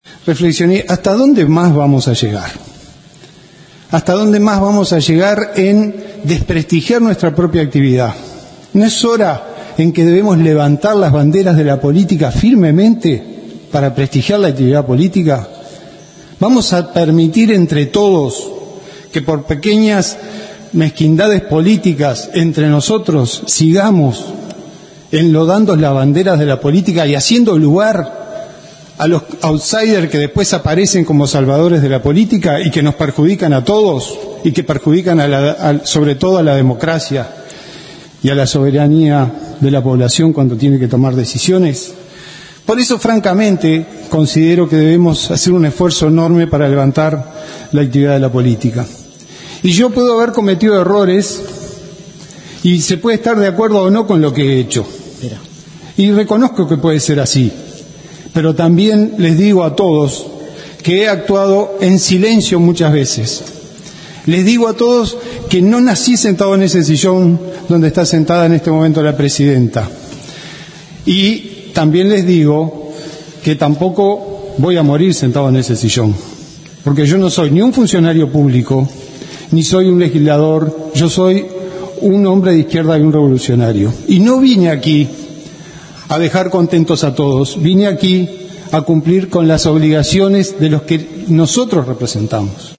El vicepresidente de la República y presidente de la Cámara de Senadores, Raúl Sendic, reflexionó en el Parlamento sobre la forma en que ha sido cuestionada su figura a nivel personal y político, a raíz de su anterior desempeño al frente de Ancap.